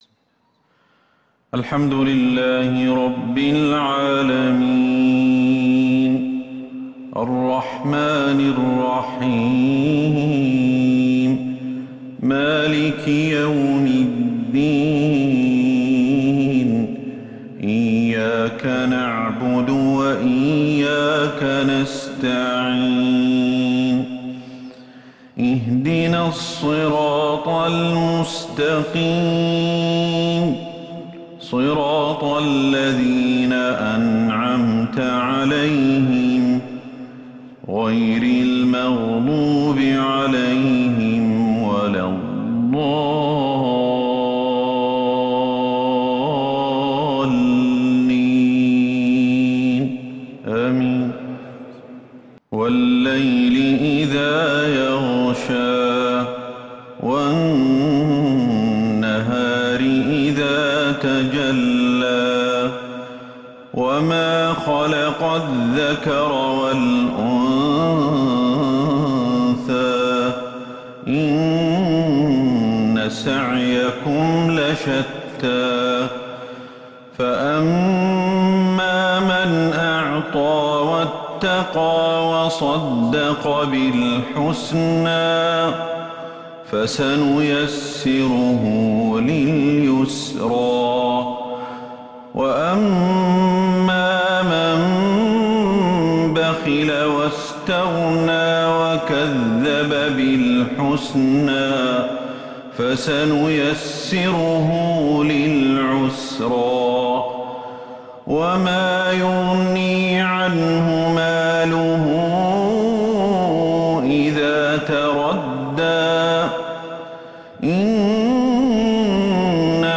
مغرب الثلاثاء 30 محرم 1443 هـ سورتي {الليل} {الضحى} > 1443 هـ > الفروض - تلاوات الشيخ أحمد الحذيفي